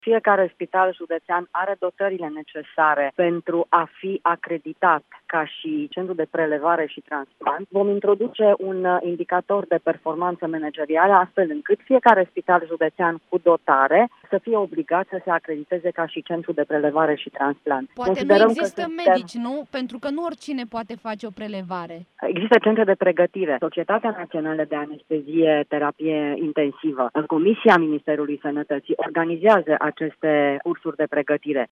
Fiecare spital judeţean va fi obligat să preleveze organe de la pacienţii în moarte cerebrală, anunţă ministrul Sănătăţii.